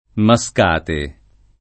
Mascate [ ma S k # te ]